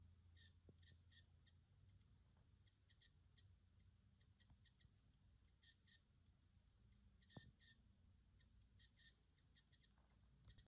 Fritz!Box 7590 macht komische Geräusche
Heute ist mir ein Geräusch an meiner Fritz!Box aufgefallen, welches ich so noch nie gehört habe. Das Geräusch hört sich für mich wie Spulenfiepen an. Die Fritz!Box ist jetzt gute 8 Jahre alt. Zum Anhören der Audiodatei empfehle ich, die Lautstärke hochzudrehen.